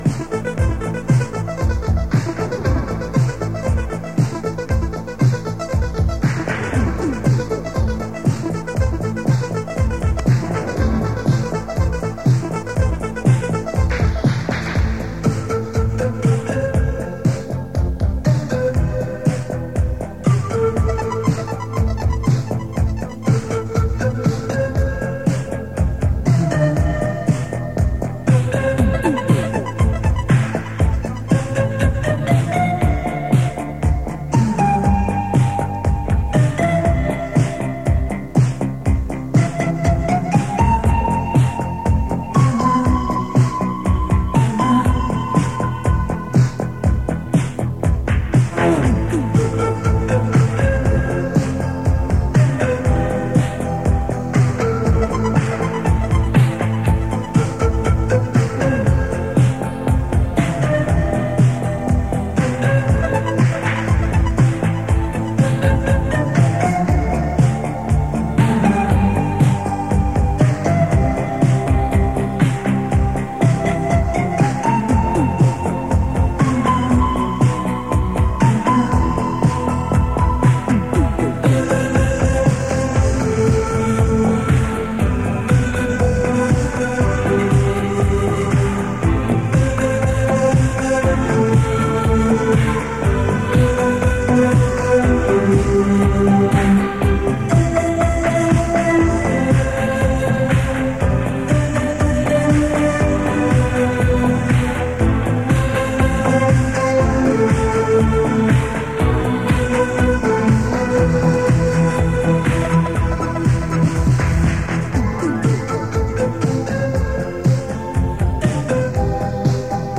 flute.mp3